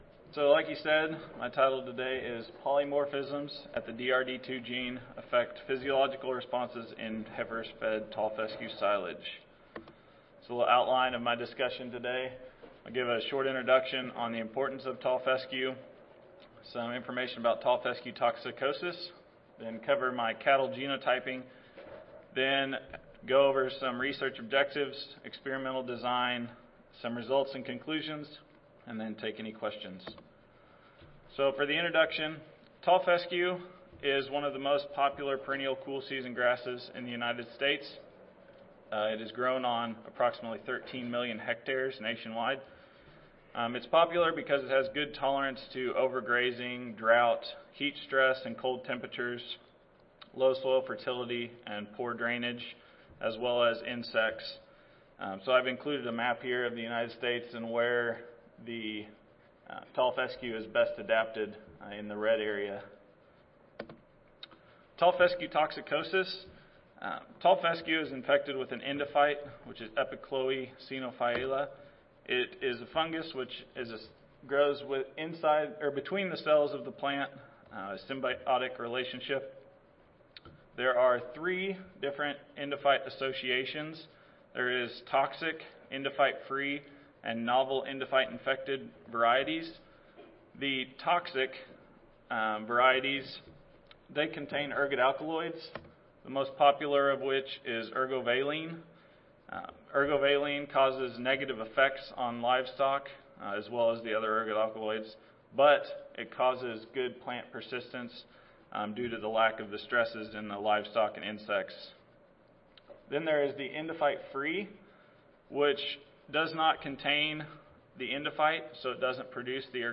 University of Tennessee Audio File Recorded Presentation